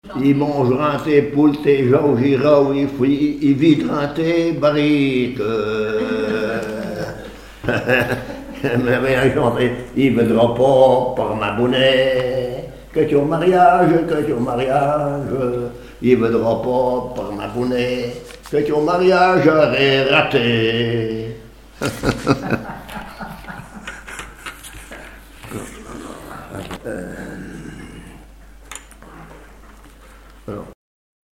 Chanson de cave
répertoire de chansons populaires et traditionnelles
Pièce musicale inédite